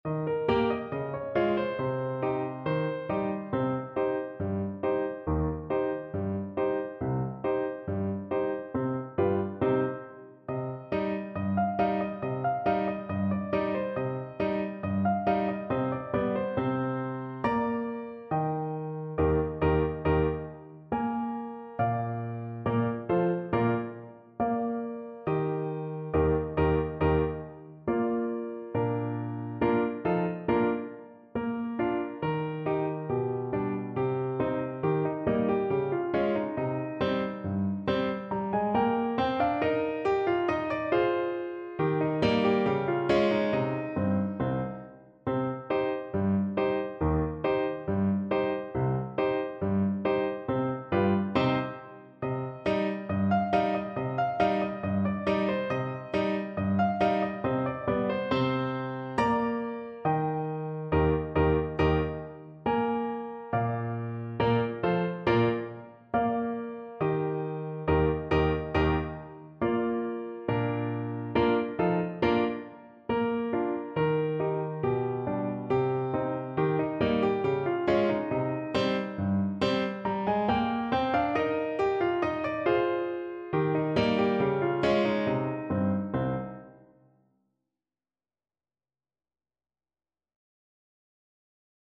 World Trad. Patch Tanz (Klezmer) Alto Saxophone version
Alto Saxophone
Traditional Music of unknown author.
2/4 (View more 2/4 Music)
C minor (Sounding Pitch) A minor (Alto Saxophone in Eb) (View more C minor Music for Saxophone )
Slow =69
World (View more World Saxophone Music)